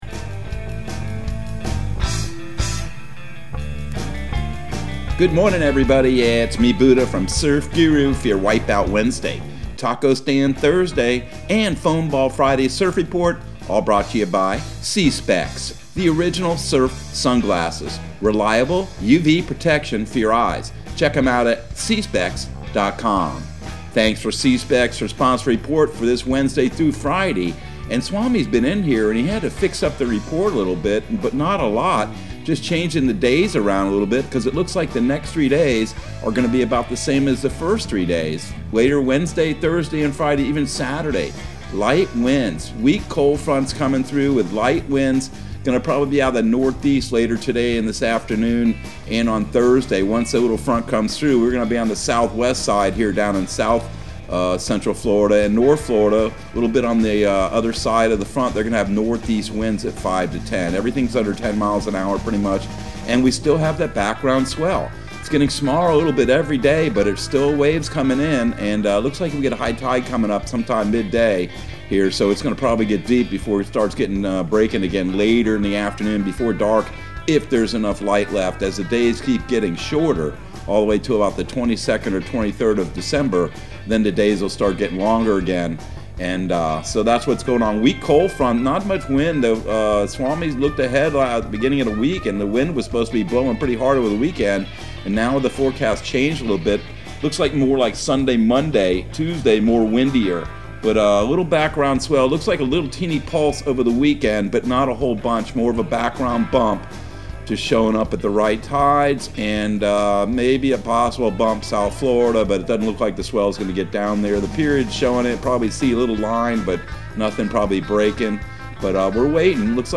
Surf Guru Surf Report and Forecast 12/08/2021 Audio surf report and surf forecast on December 08 for Central Florida and the Southeast.